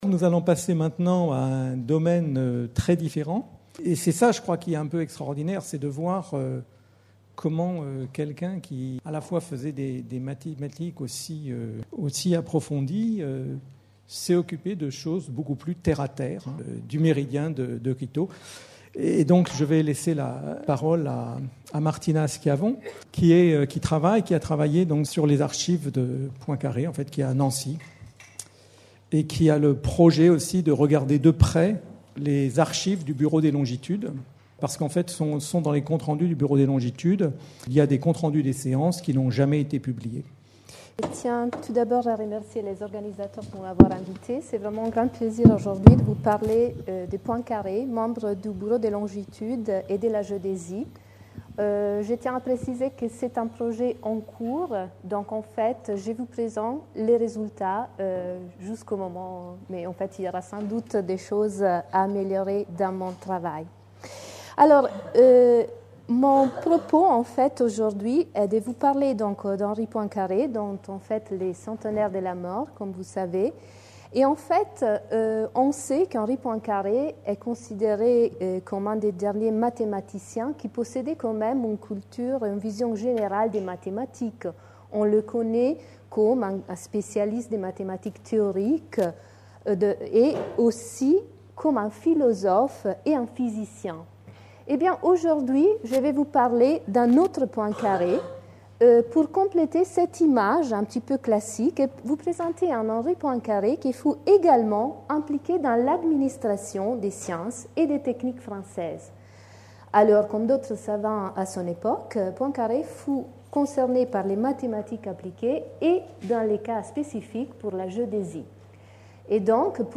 Journée de commémoration du centenaire de la disparition de Henri Poincaré (29 avril 1854 - 17 juillet 1912), organisée à l'Institut d'Astrophysique de Paris le 9 Juillet 2012.